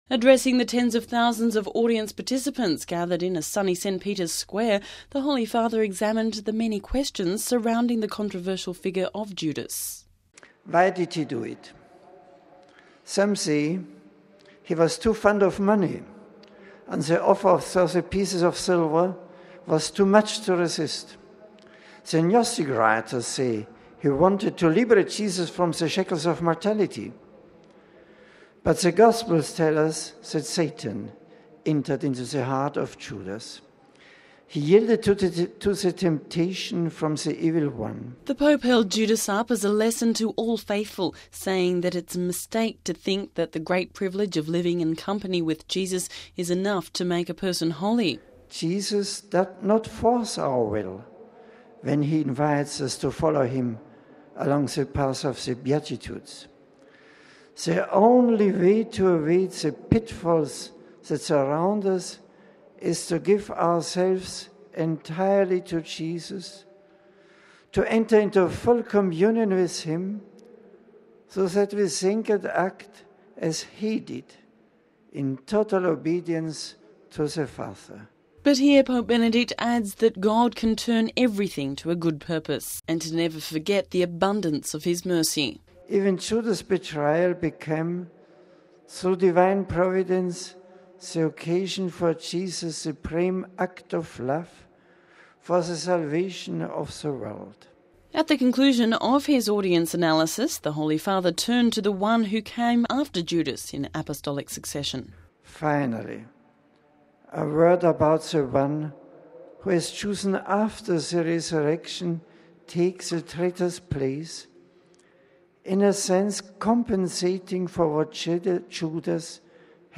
Home Archivio 2006-10-18 18:40:49 Pope Benedict Speaks of Judas Iscariot (19 Oct '06 - RV) Pope Benedict XVI spoke of the apostle Judas Iscariot at Wednesday's general audience.